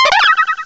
cry_not_mimejr.aif